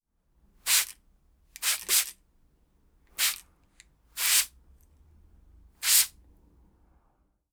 spray-bottle.wav